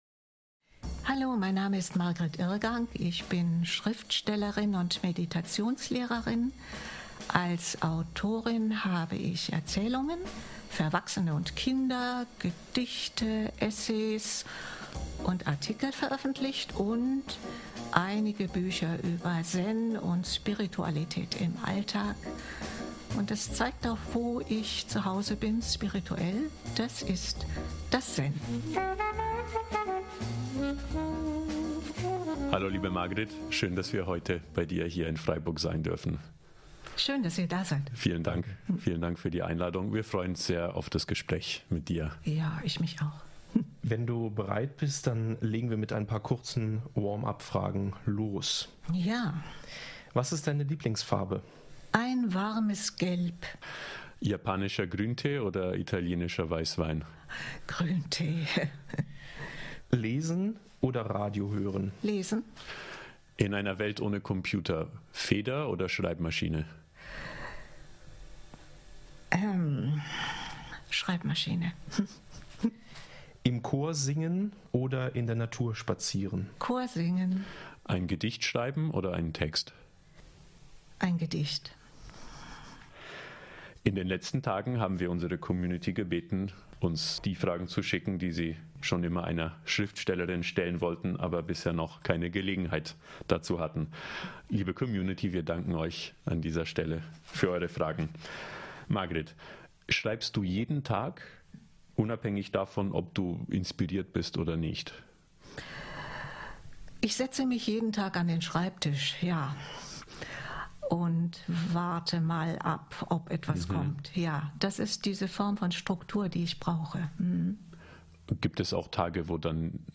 Das Interview wurde am 31. August 2024 aufgezeichnet.